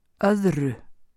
uttale